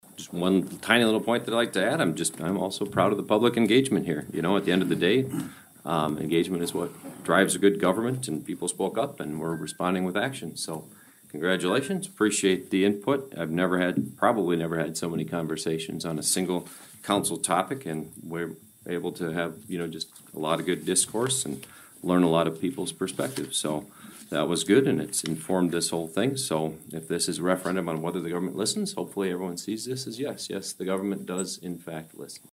ABERDEEN, S.D (Hub City Radio)- At the Aberdeen, SD City Council meeting earlier this Monday evening, October 6th, the City Council voted unanimously on an 8-0 vote to keep downtown Main Street as a one-way road.
Aberdeen Mayor Travis Schaunaman discuss the role of the voters.